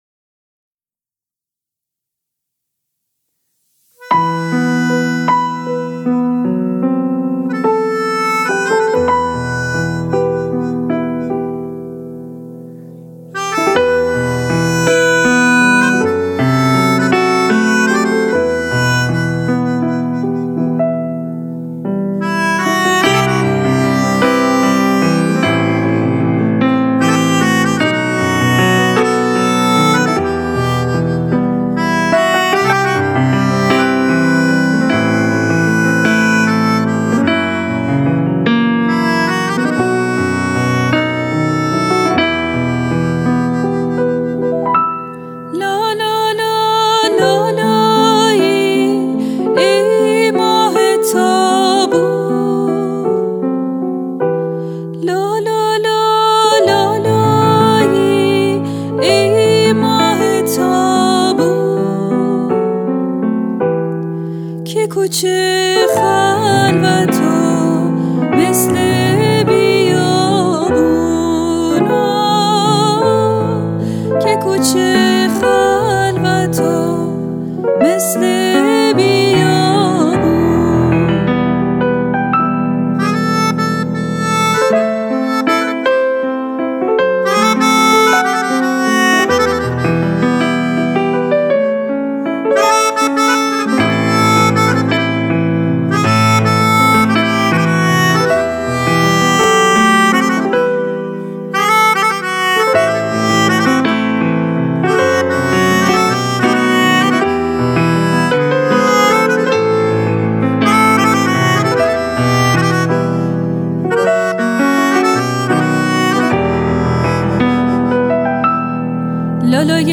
لالایی زیبا